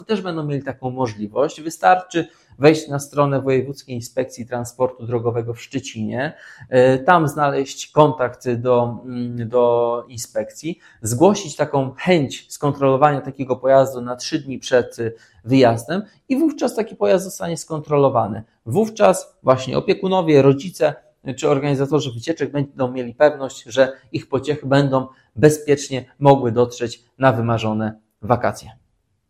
Gdzie rodzice mogą zgłosić się by sprawdzić taki autokar przed wyjazdem o tym mówi dla Twojego Radia wicewojewoda Bartosz Brożyński